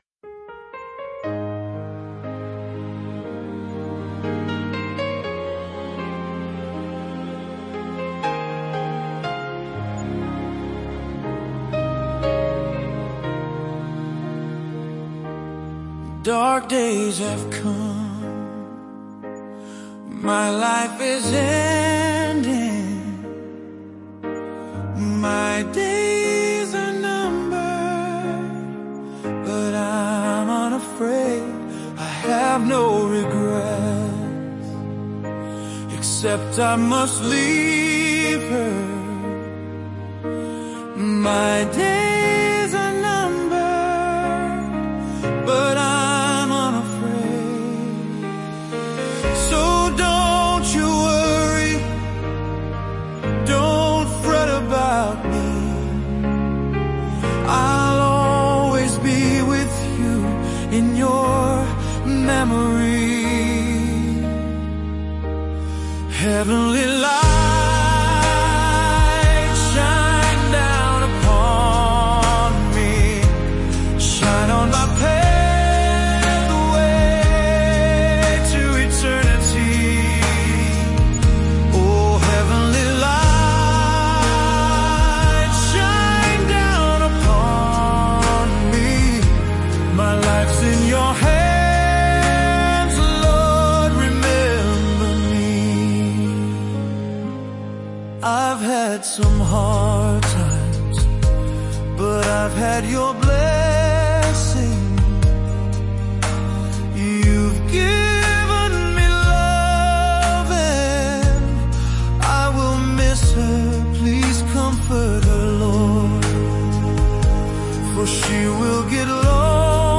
Gorgeous ballad.